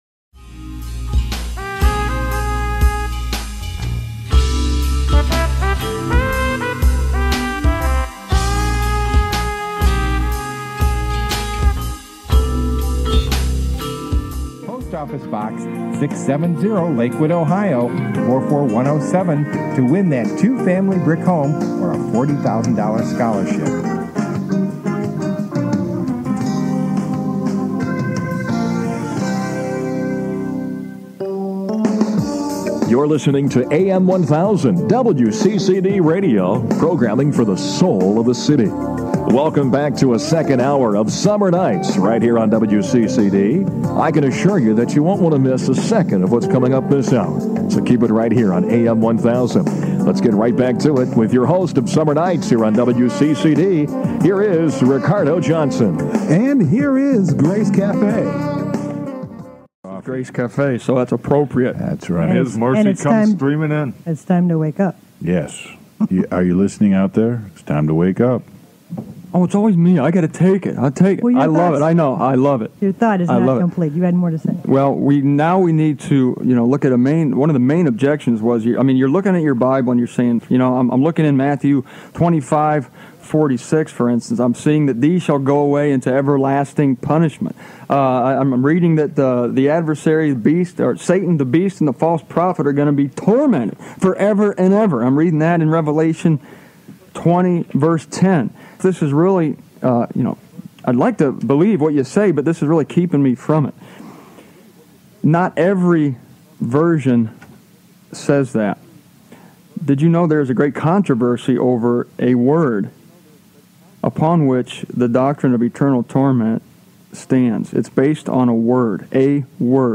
And for two hours a night for five days on live radio, we talked about the most important topic that few people ever talk about.